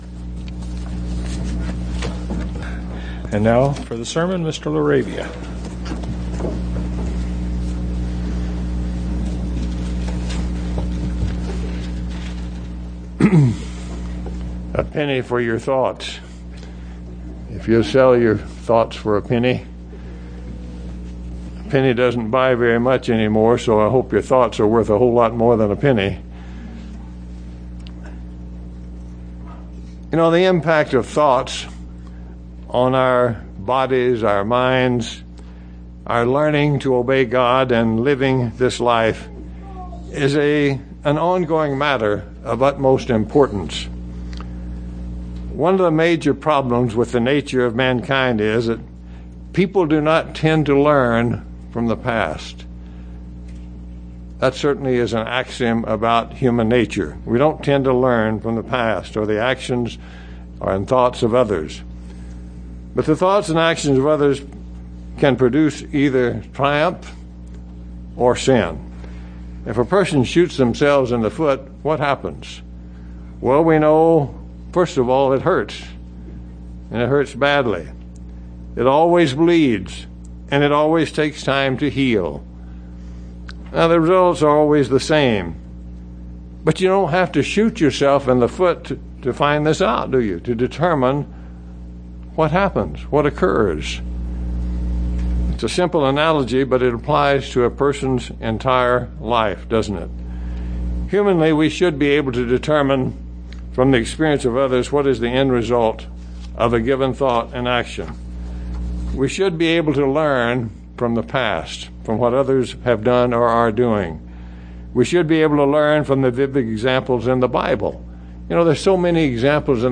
Given in Tucson, AZ El Paso, TX
UCG Sermon Studying the bible?